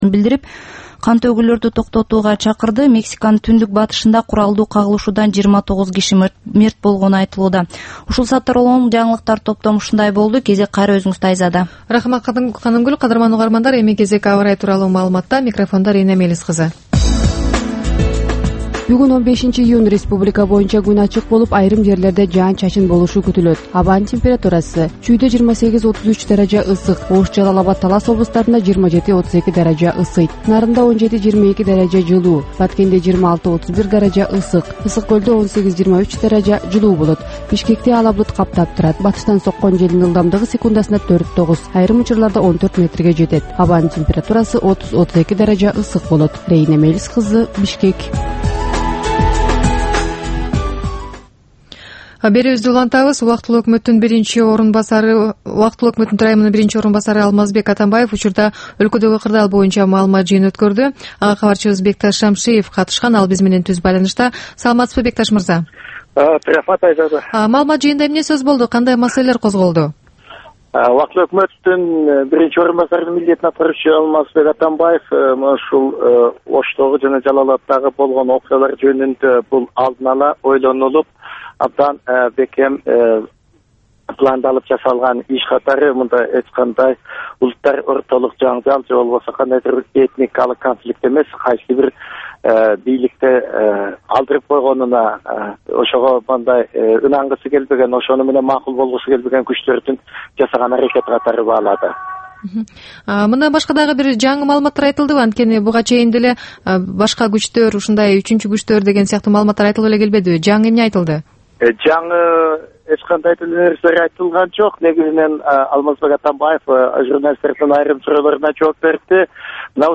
Бул түшкү үналгы берүү жергиликтүү жана эл аралык кабарлар, ар кыл орчун окуялар тууралуу репортаж, маек, талкуу, кыска баян жана башка оперативдүү берүүлөрдөн турат. "Азаттык үналгысынын" бул чак түштөгү алгачкы берүүсү Бишкек убакыты боюнча саат 12:00ден 12:15ке чейин обого чыгарылат.